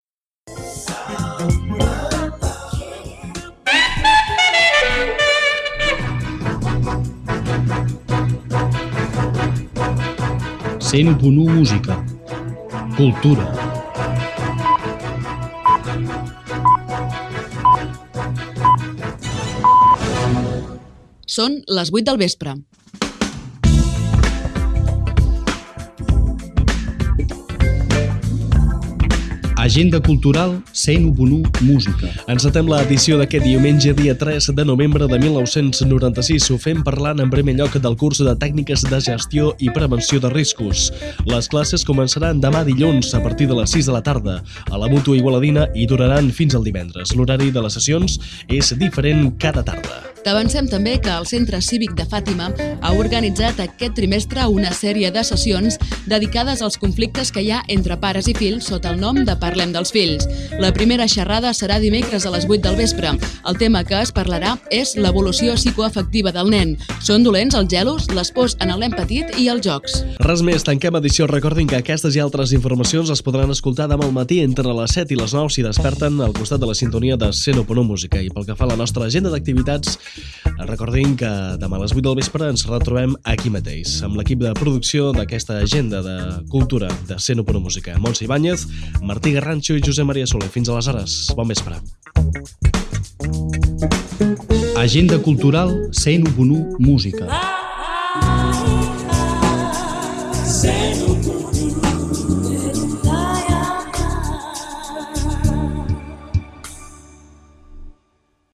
Indicatiu de l'emissora, hora, indicatiu del programa i data. Informacions: curs de prevenció de riscos i xerrades adreçades als pares. Comiat amb els noms de l'equip i identificació de l'emissora.
Informatiu
FM